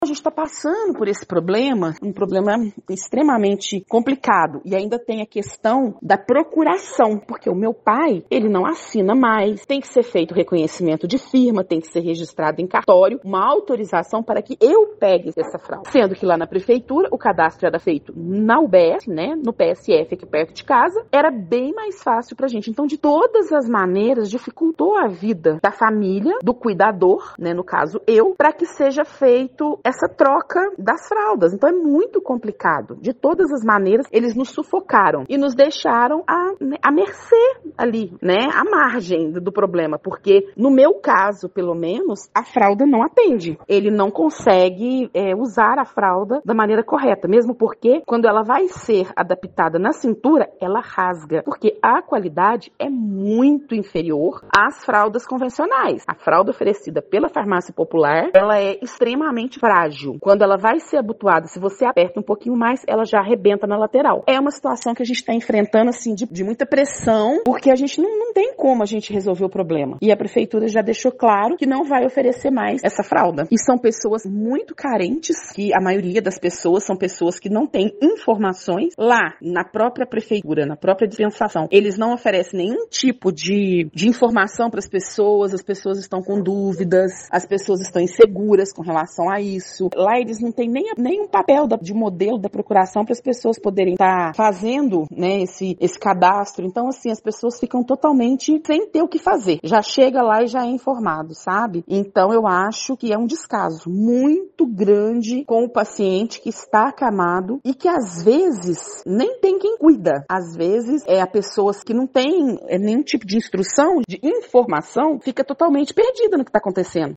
Cuidadora do pai